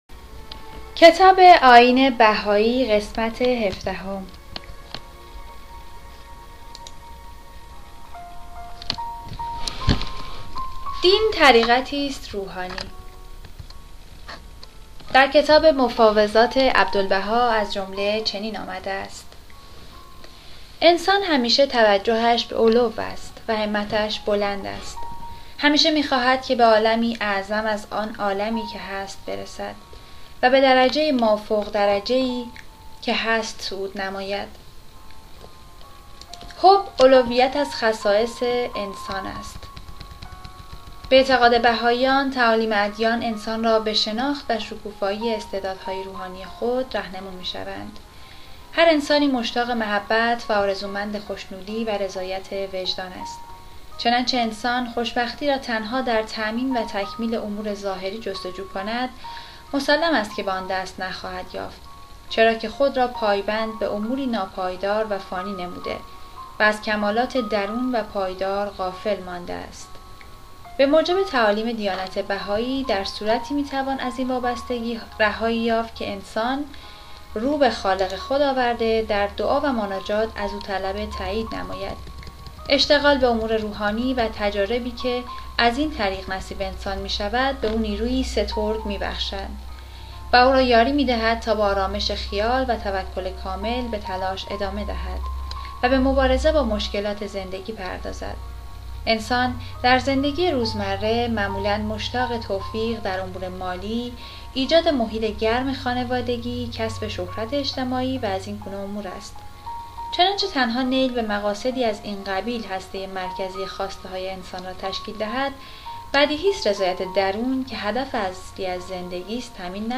کتابهای صوتی